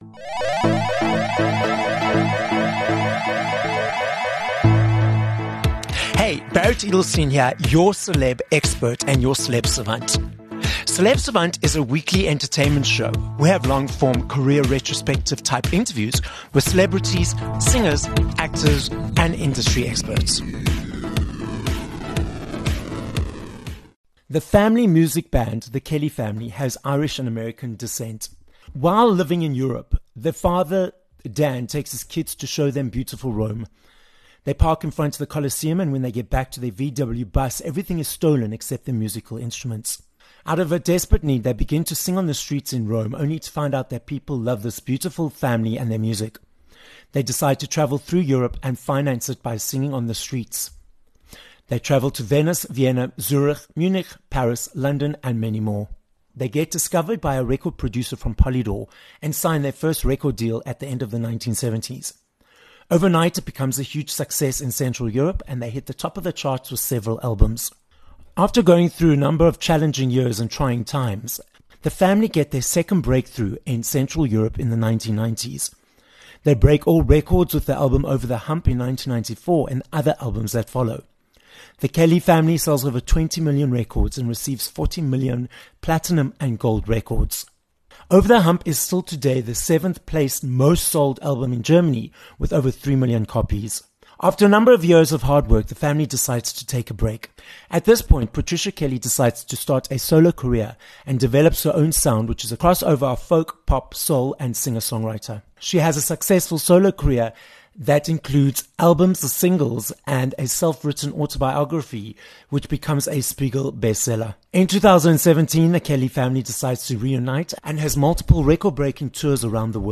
14 Jan Interview with Patricia Kelly
With a multi-platinum and award-winning career as part of The Kelly Family and as a solo artist, Patricia Kelly joins us from Germany on this episode of Celeb Savant. Patricia explains how at the age of 5 she used to get upset that her family would leave her when they would go perform on the weekends, which led to her father challenging her to learn all the songs... and the rest is a successful journey in the music world.